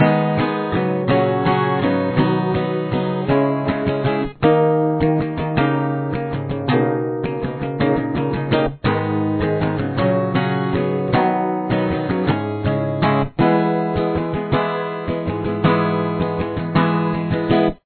Guitar 1 :